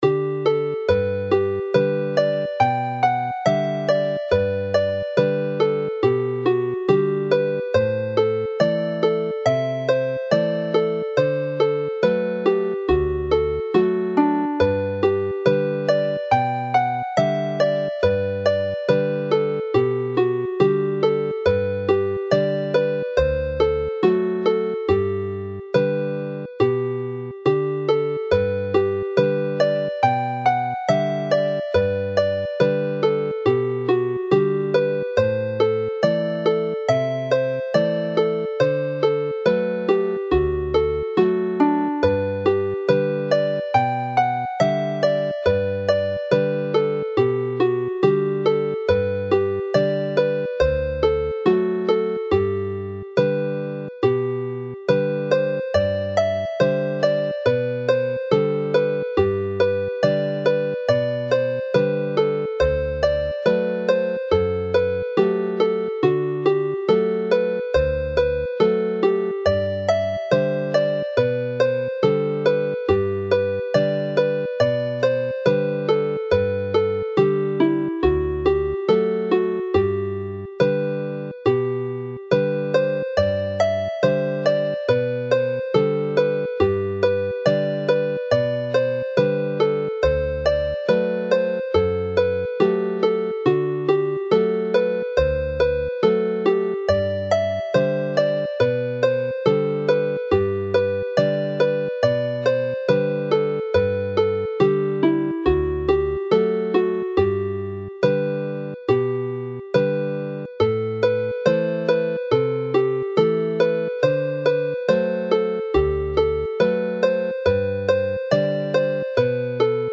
Play the melody very slowly